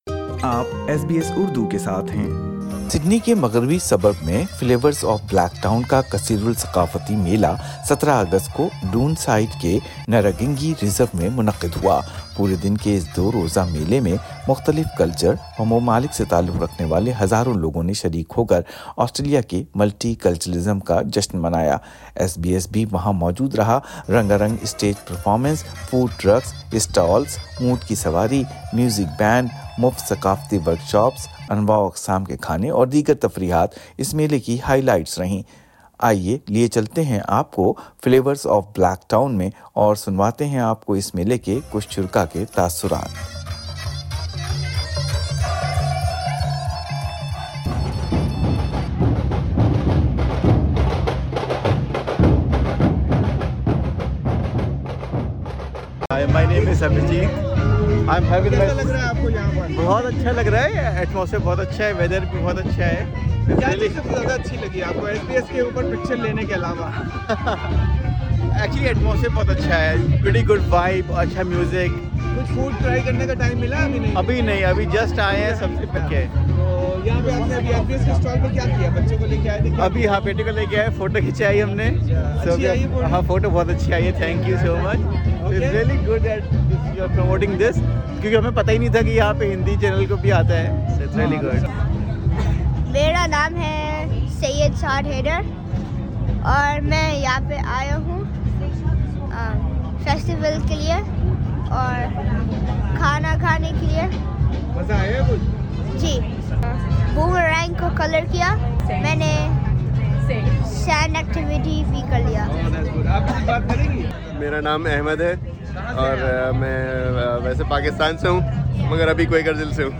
Community members from various backgrounds who attended the festival spoke to SBS Urdu, sharing their thoughts on the event.